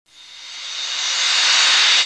whish
whish.mp3